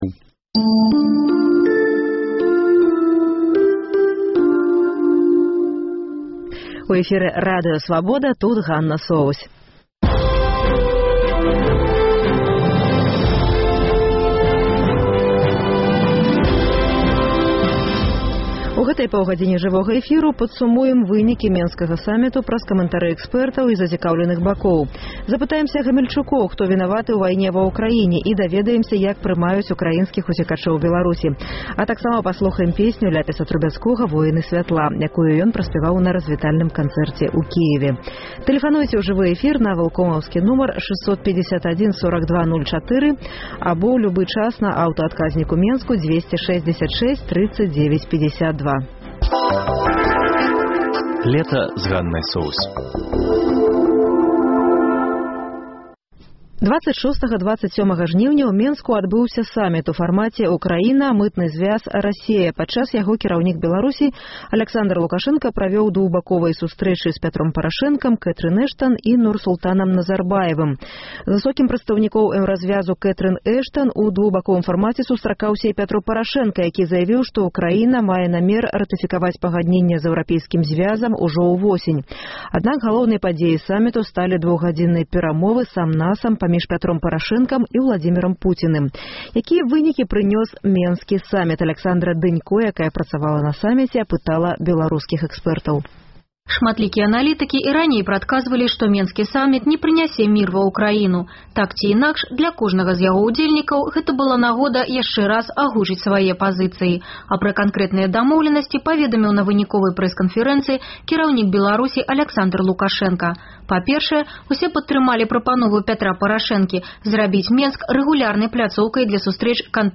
Ці ёсць на школьных кірмашах сшыткі і дзёньнікі па-беларуску? Рэпартаж Свабоды.